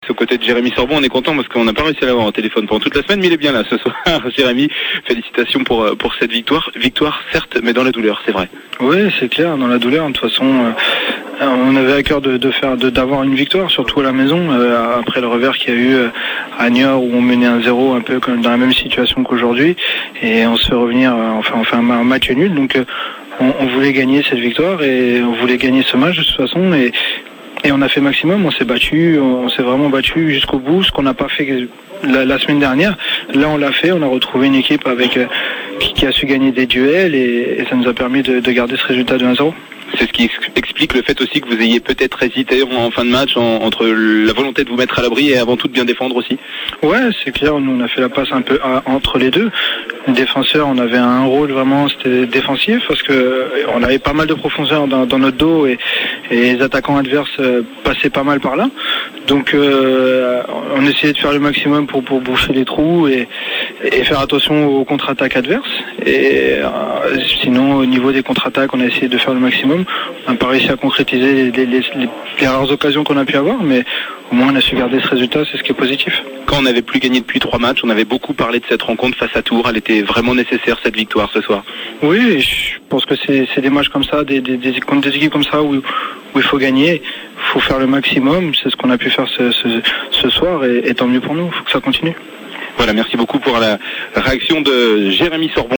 interview complète